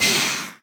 sounds / mob / phantom / hurt2.ogg
hurt2.ogg